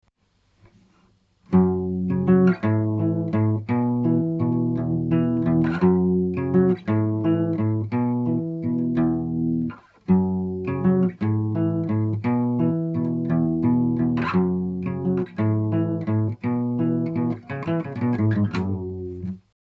Специально сделал запись До и После. На страрых уже эффект Wah-Wah появился:gg:А так, не заметно особо было.
Но все равно, слышите, нет густого, глубокого звука.